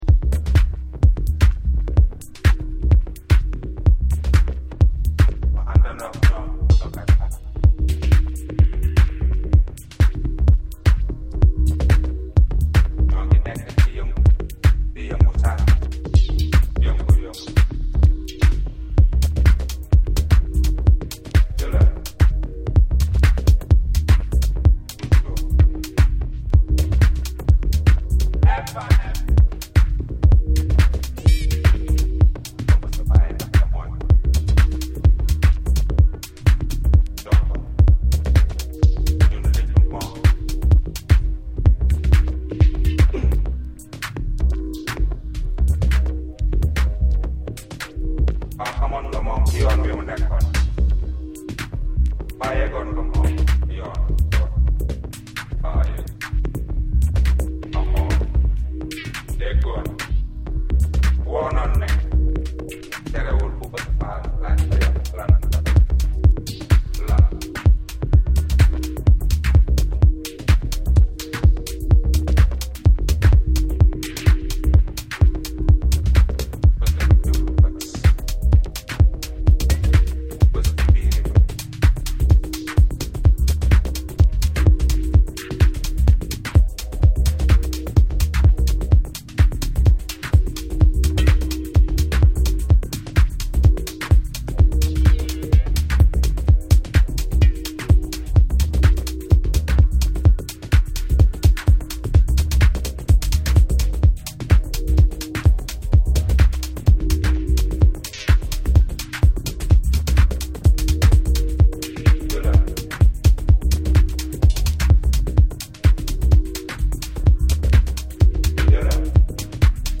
captivating tones of Senegalese vocalist